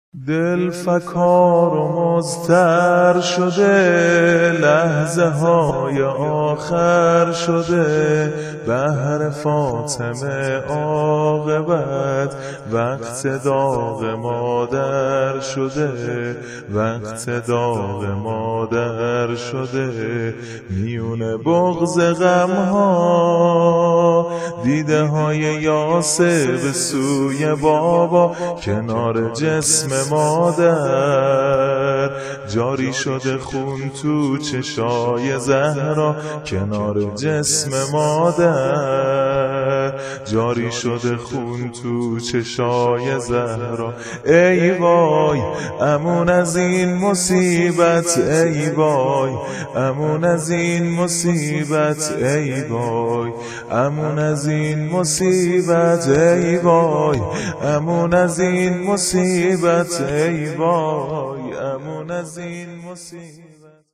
دانلود متن و سبک زمینه وفات حضرت خدیجه کبری (س) -( دل فكار و مضطر شده لحظه هاي آخر شده )